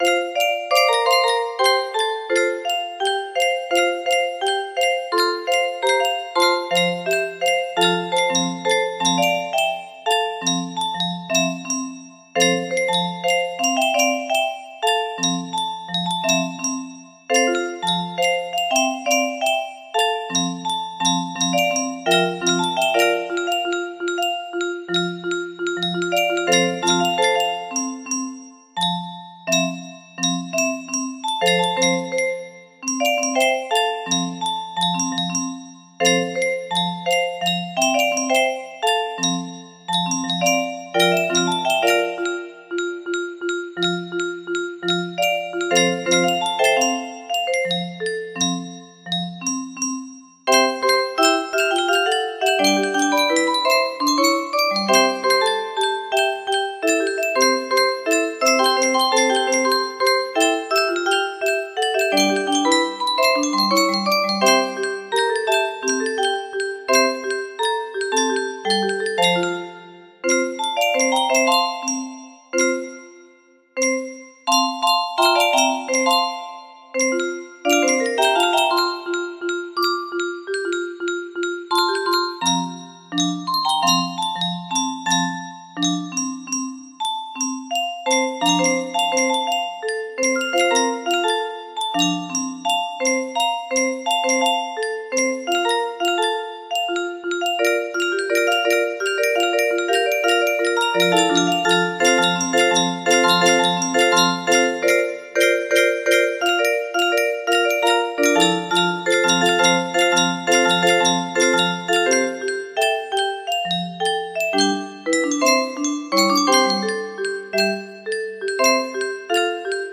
Grand Illusions 30 (F scale)
even at 30f scale this puppy sports over 1000 notes!